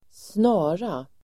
Uttal: [²sn'a:ra]